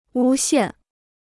诬陷 (wū xiàn): to entrap; to frame.